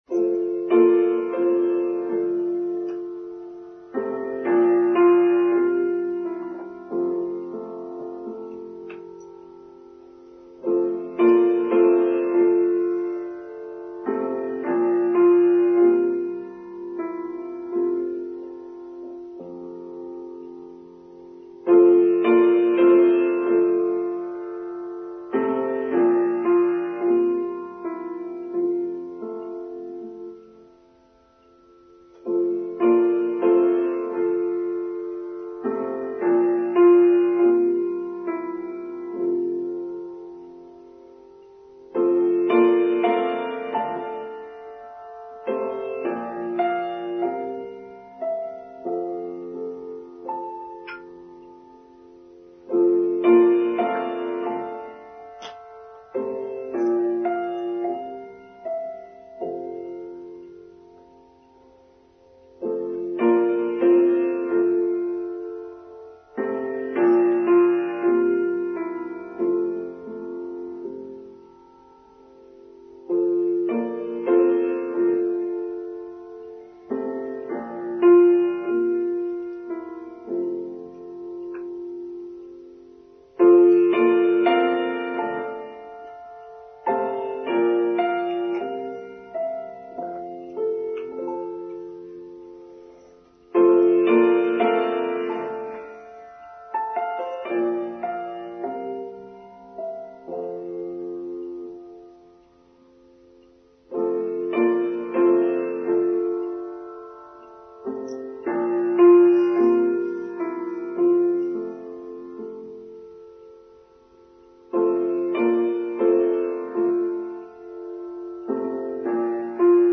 Using Our Inner Eyes: Online Service for Sunday 13rd August 2023